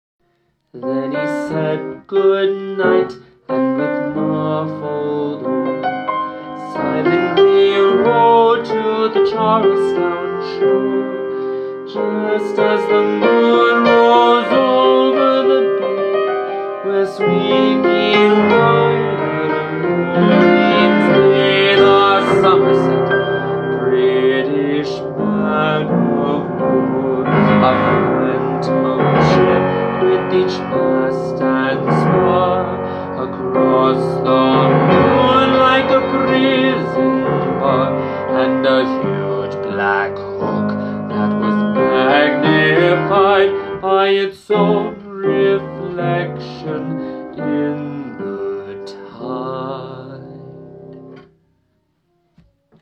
Easy Piano and Voice